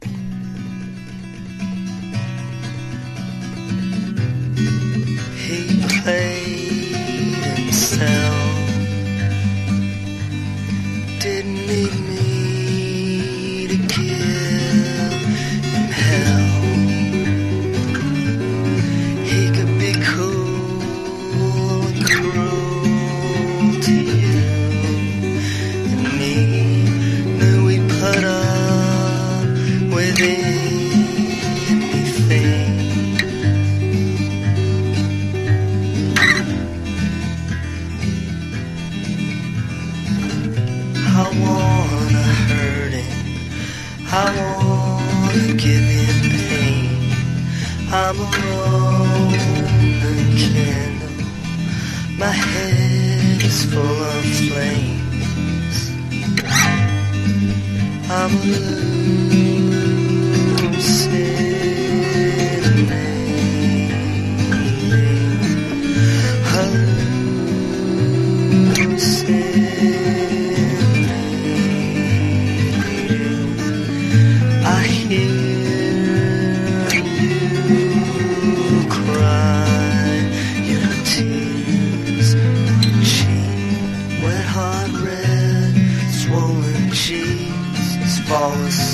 1. 90'S ROCK >